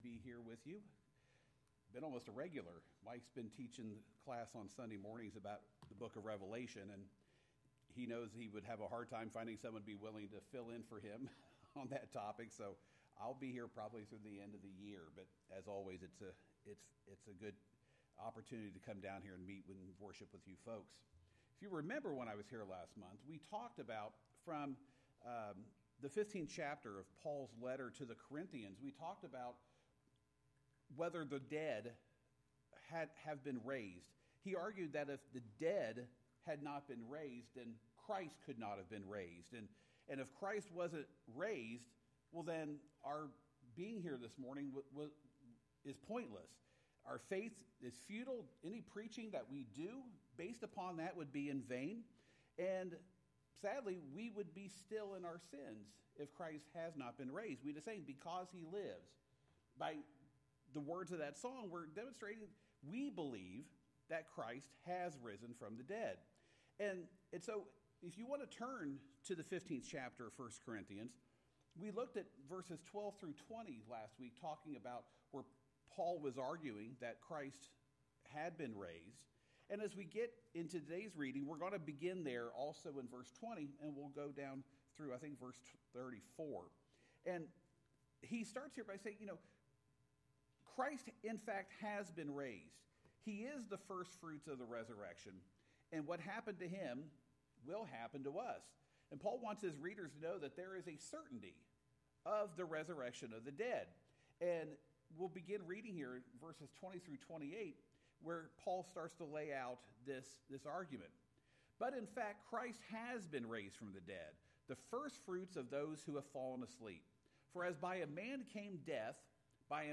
Lesson Recording: